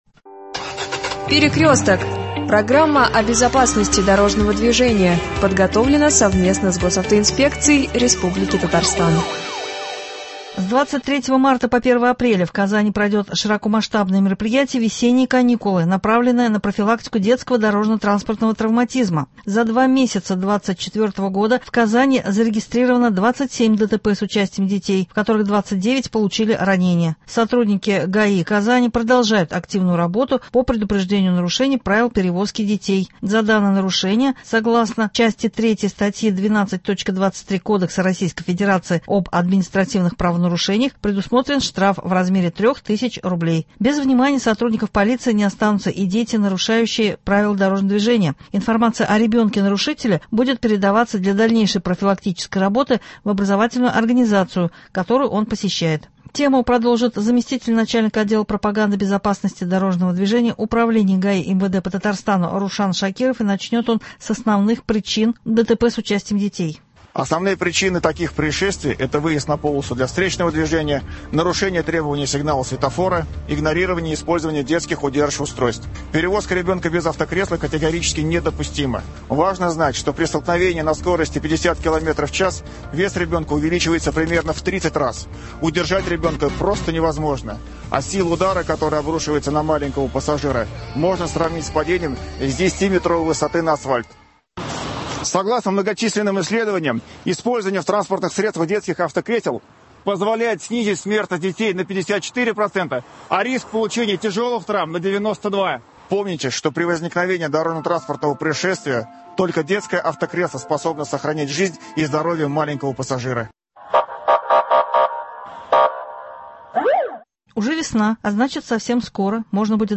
Об этом – беседа